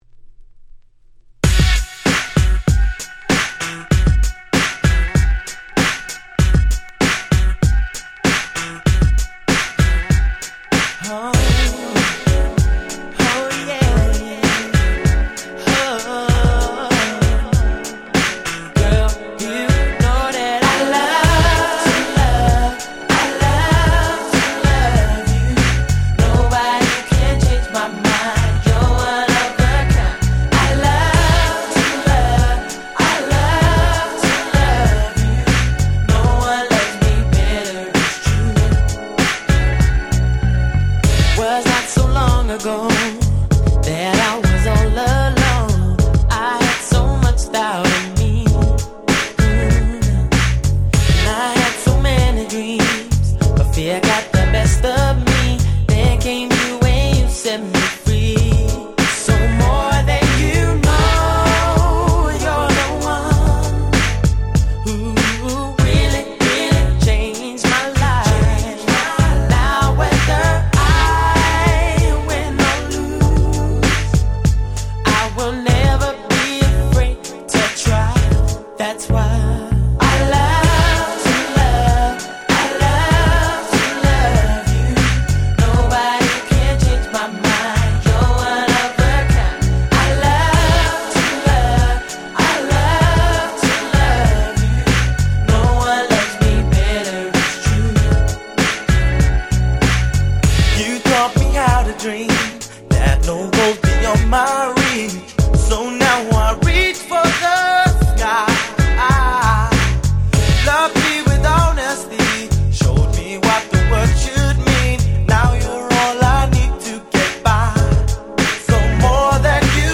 97' Very Nice R&B !!